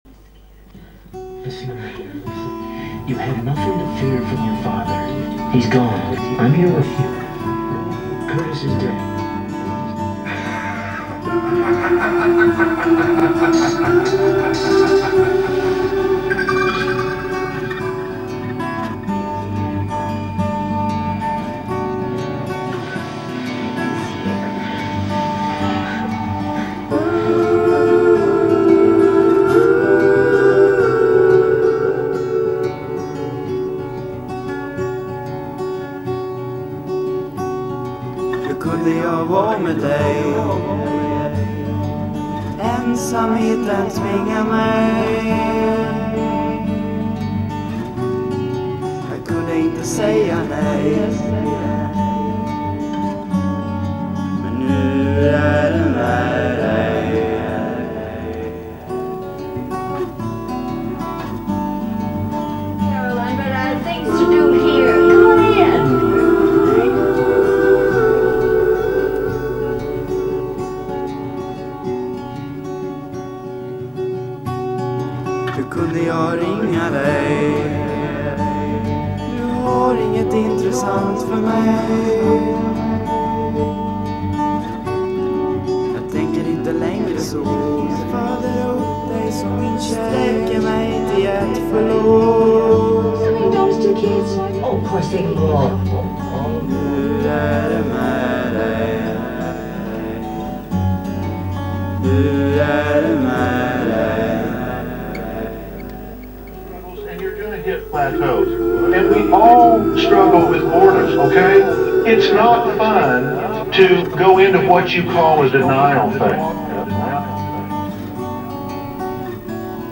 Vilken suggestiv låt, tack för två veckor som gått, ser fram emot dom som kommer.
Det är en livesampling från tv.
Recordknappen på och tv-volymen lite upp och ner. En tagning! tack tvn!